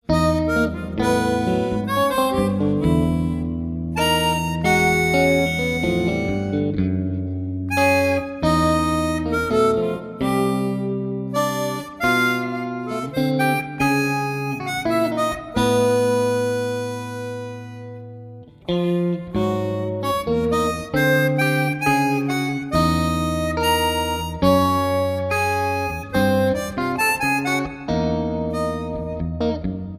Guitar
Harmonica